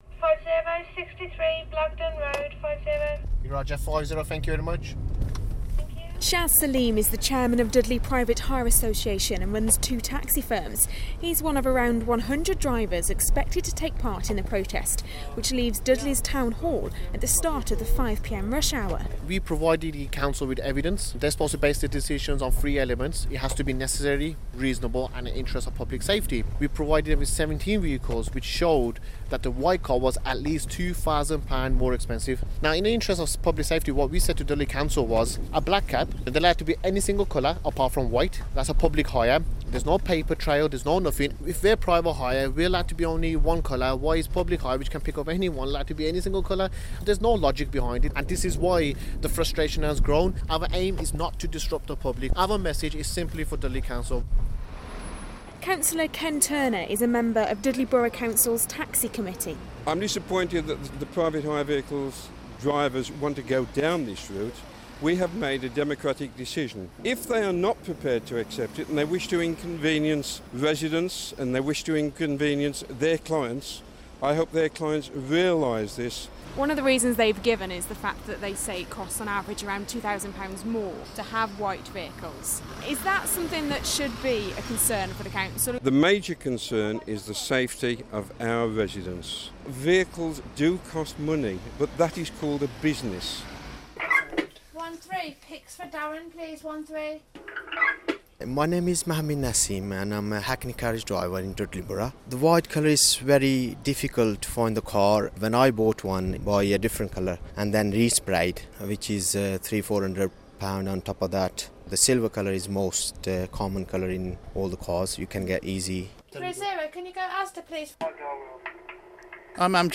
(Broadcast on BBC WM April 2013)